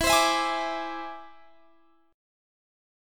Listen to E+7 strummed